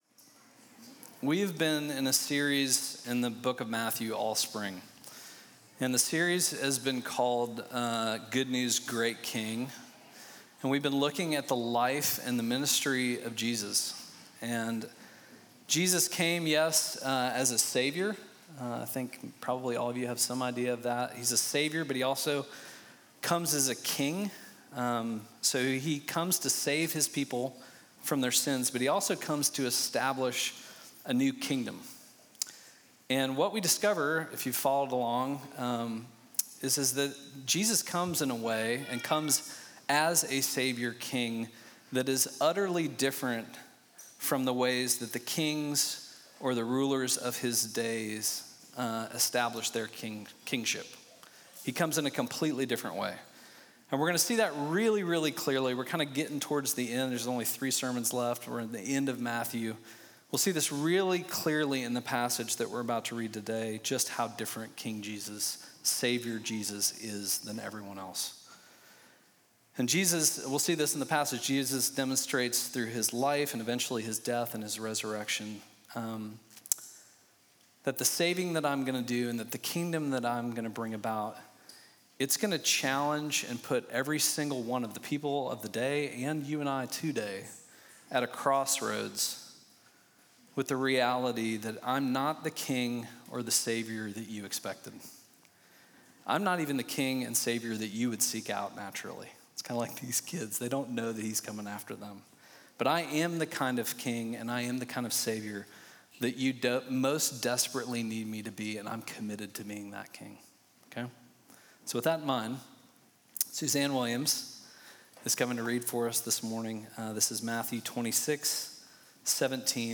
Midtown Fellowship Crieve Hall Sermons Triumph of the King: Passover May 19 2024 | 00:36:19 1x 00:00 / 00:36:19 Subscribe Share Apple Podcasts Spotify Overcast RSS Feed Share Link Embed